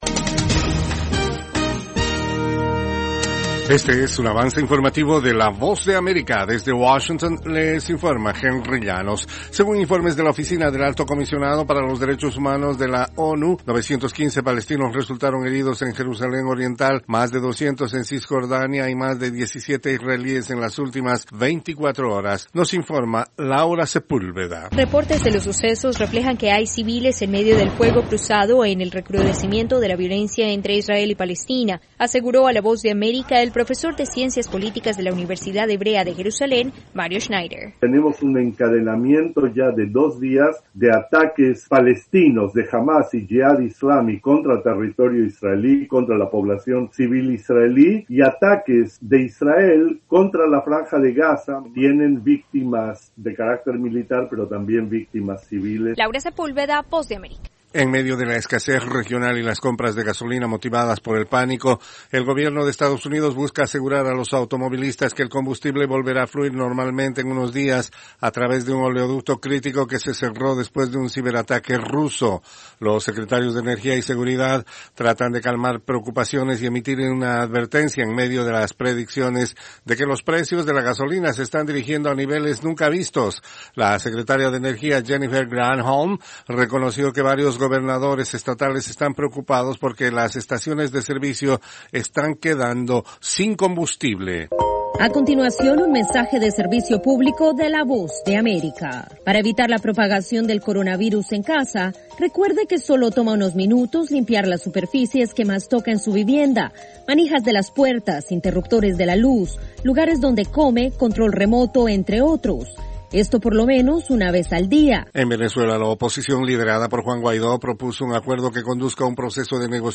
Cápsula informative de tres minutes con el Acontecer noticioso de Estados Unidos y el mundo.